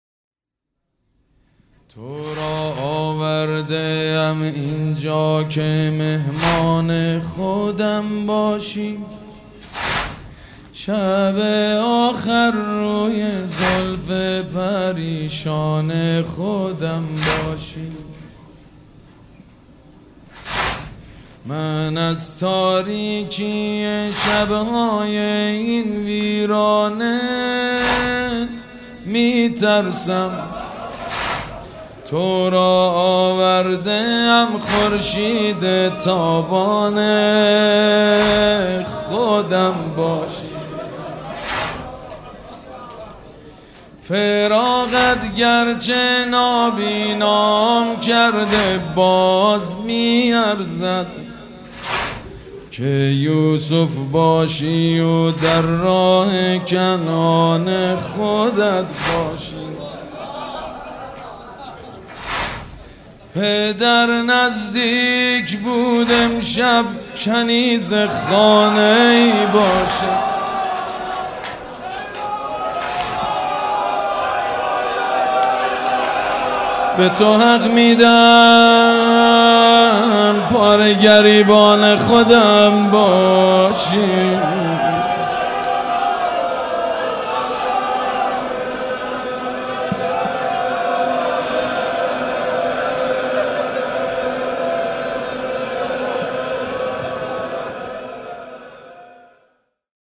شب سوم در حسینیه ریحانه الحسین